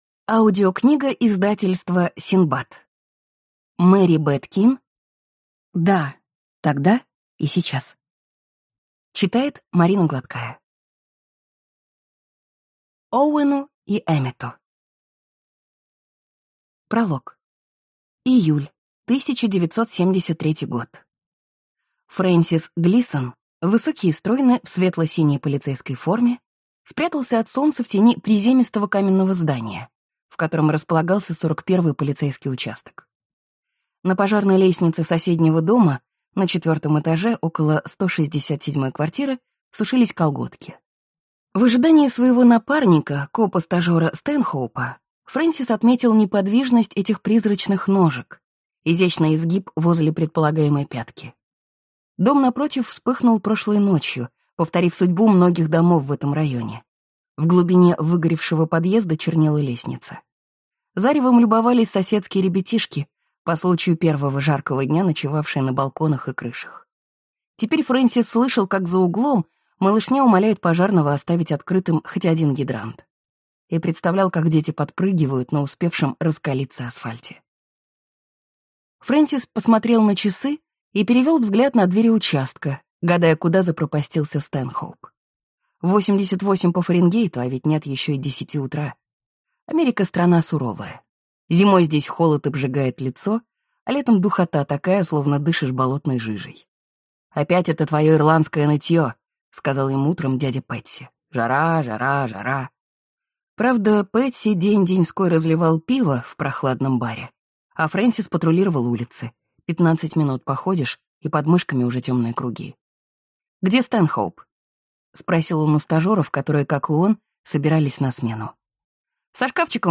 Аудиокнига Да – тогда и сейчас | Библиотека аудиокниг